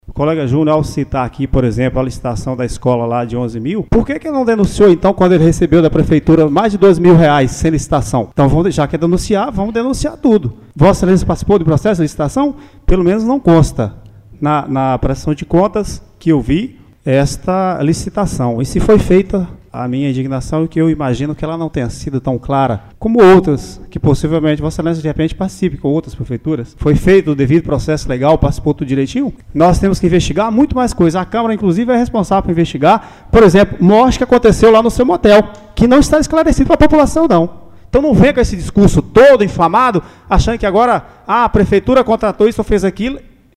A Sessão Ordinária na última terça-feira, 18 de junho, realizado no plenário da Câmara de Vereadores de Carinhanha, no oeste da Bahia, foi marcada por denúncia e troca de acusações entre os parlamentares Júnio Souza Guedes, Júnio Guedes (PSDB) e Ronaldo Moreira Cassiano, Ronaldo Cassiano (PT).
Clique-aqui-para-ouvir-a-Fala-de-Ronaldo.mp3